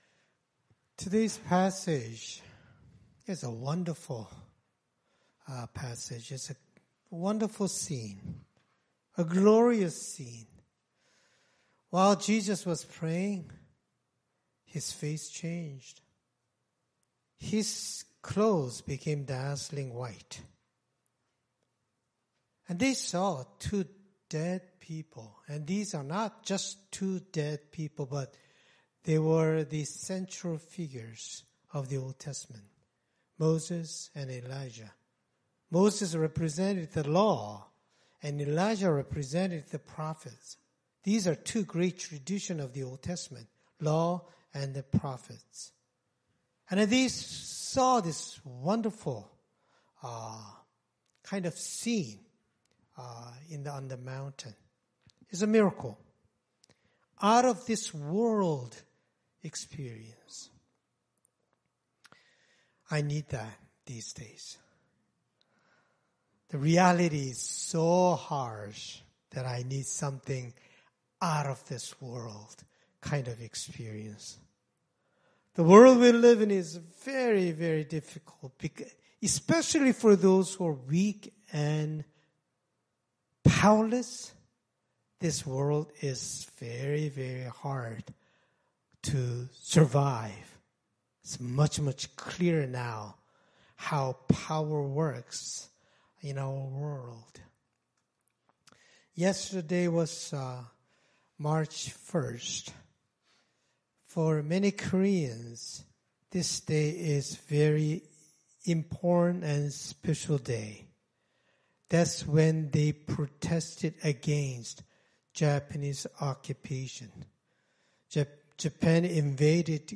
Scripture Passage Luke 9:28-36 Worship Video Worship Audio Sermon Script Today’s passage gives us a wonderful scene.